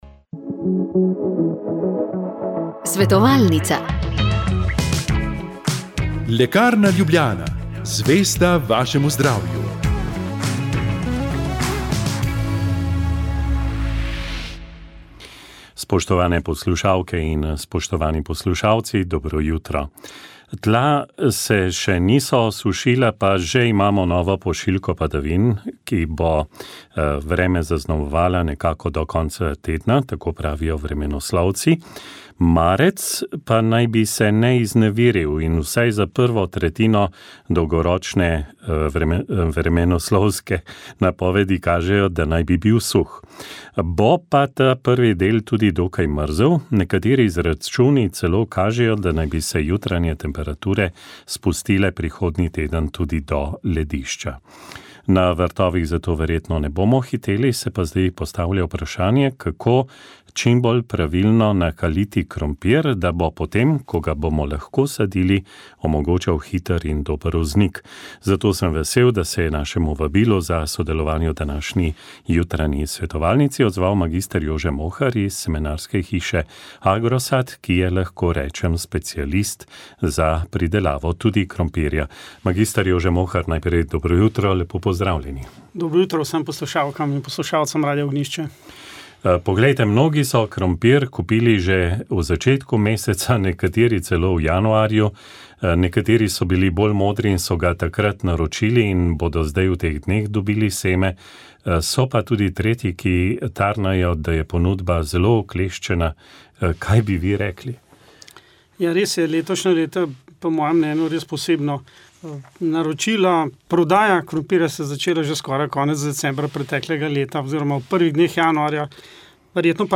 Posnetek programa Radia Ognjišče dne 25. februar 2025 ob 21-ih